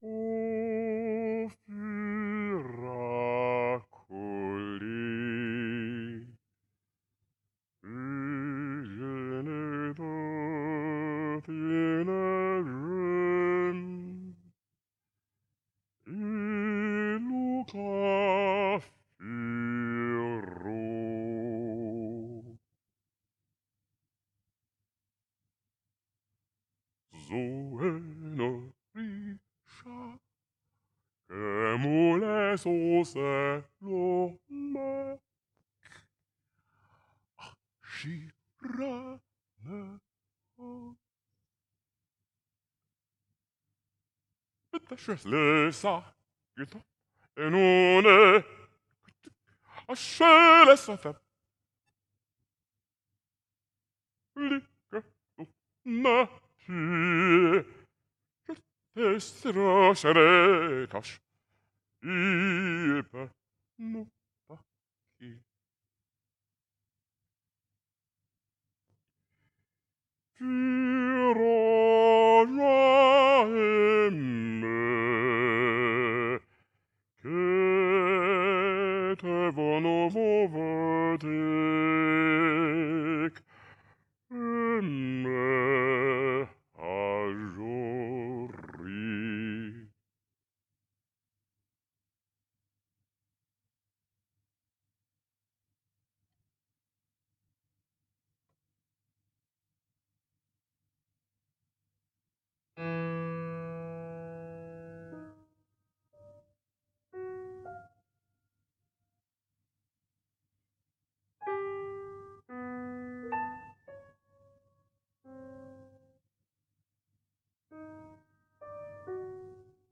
baritone, clarinet [4']